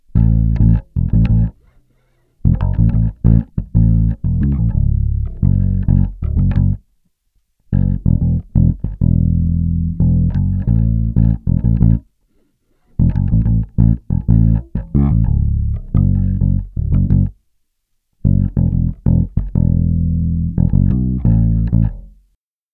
boah, klingt das gehetzt...